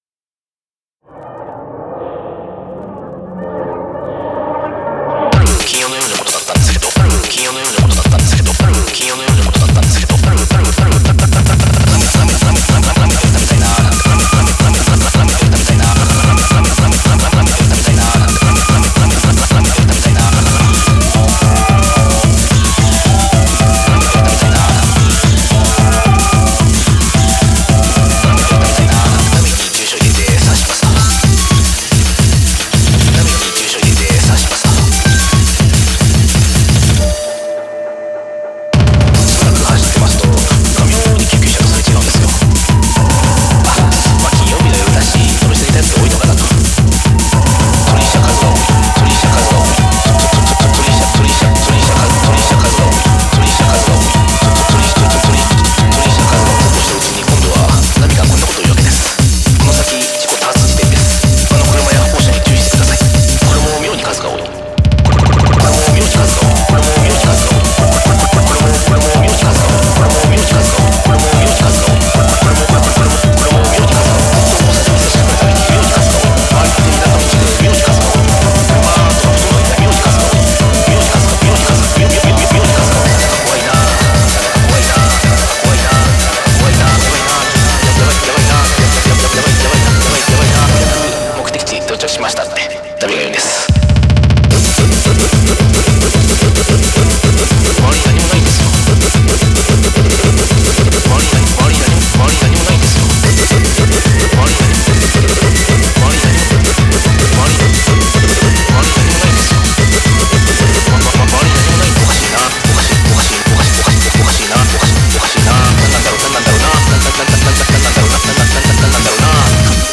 BPM220
Audio QualityPerfect (High Quality)
rotterdam techno remix